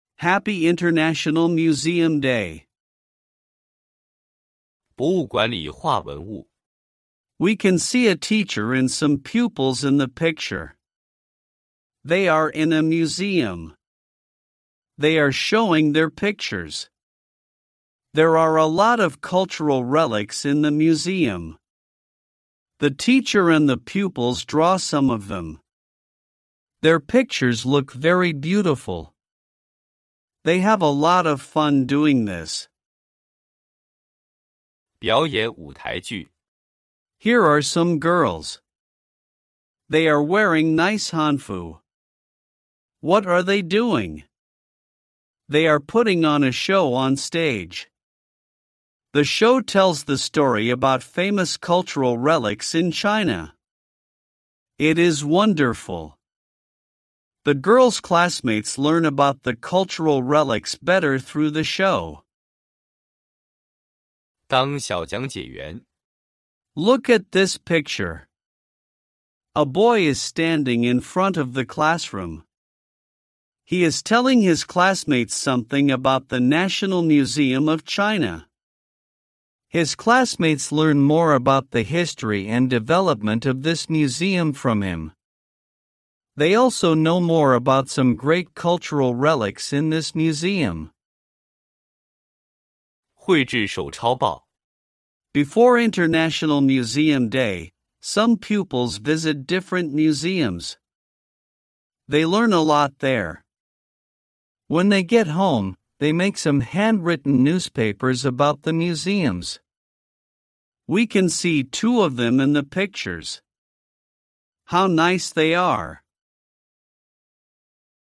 2026年5月6日五年级阅读音频